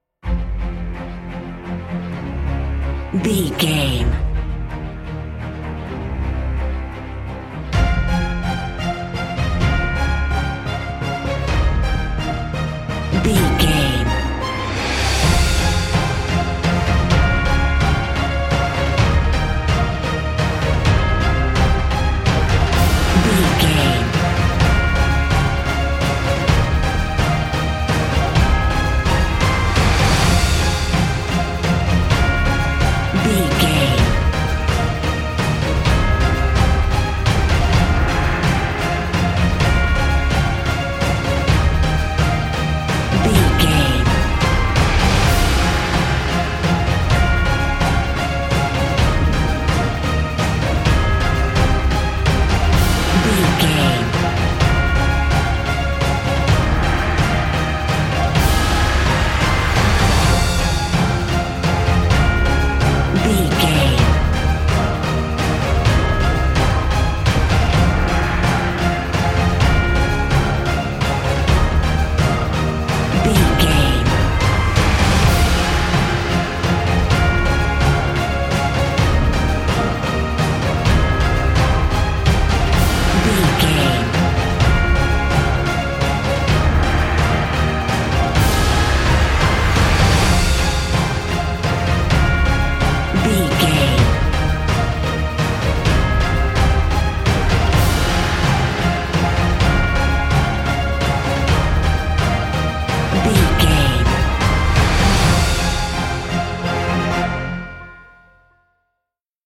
Epic / Action
Fast paced
Aeolian/Minor
Fast
heavy
hybrid
brass
cello
drums
strings
synthesizers